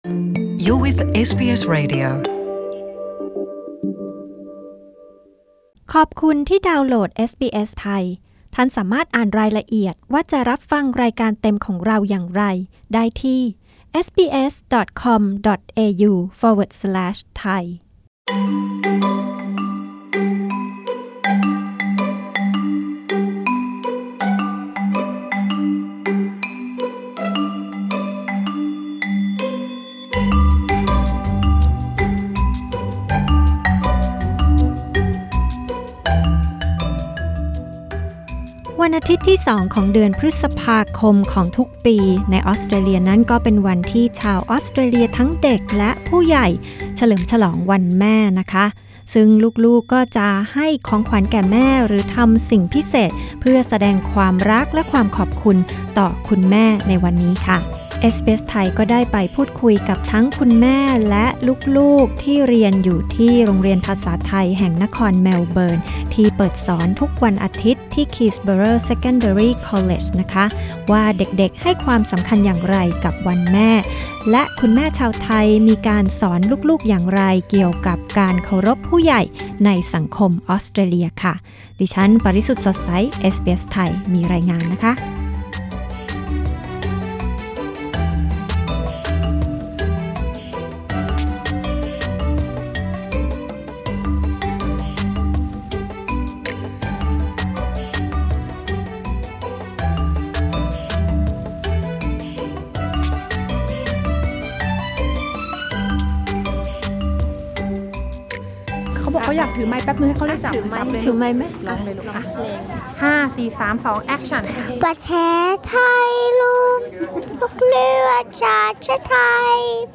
คุณแม่ชาวไทยในออสเตรเลียเผยวิธีเลี้ยงลูกให้รู้จักเคารพผู้ใหญ่ในสังคมตะวันตก Source: SBS Thai